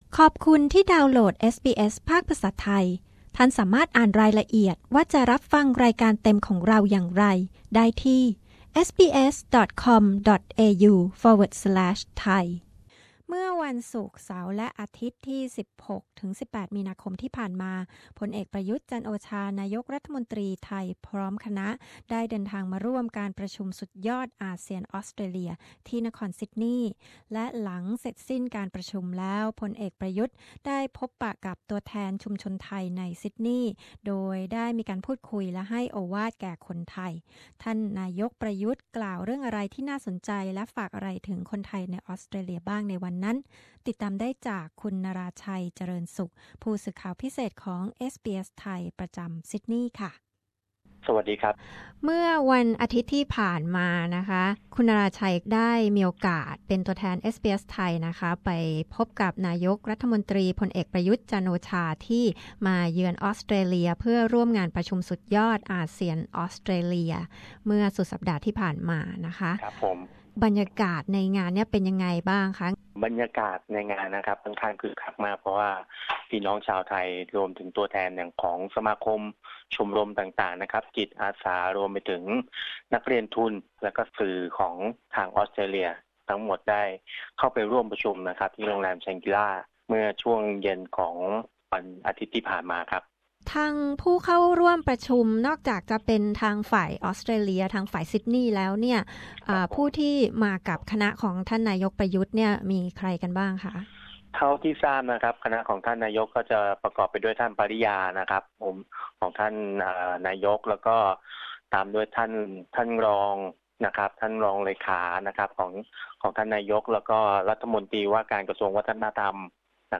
บรรยากาศนายกตู่พบประชาชนที่ซิดนีย์
Gen.Prayut Chan-ocha's meeting with Thai community representatives in Sydney.